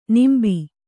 ♪ nimbi